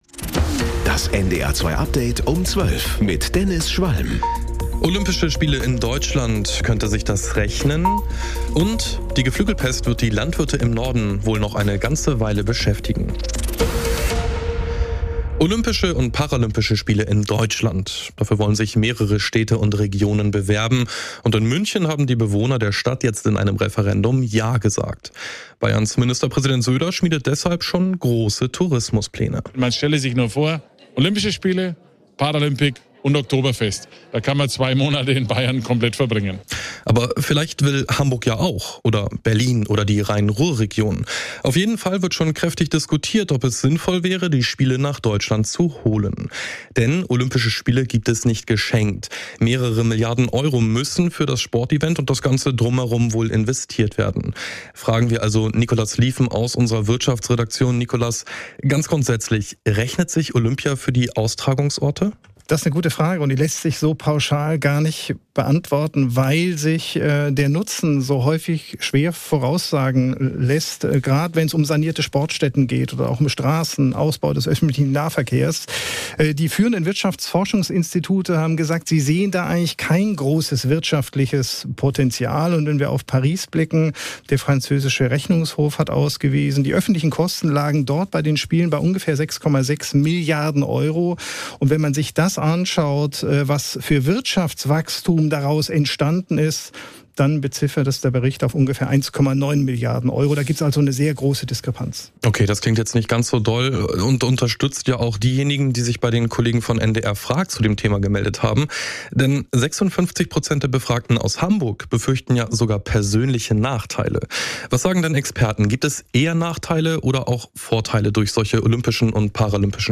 NDR 2 Tägliche Nachrichten Nachrichten NDR News Kurier Um 12 Update Um 12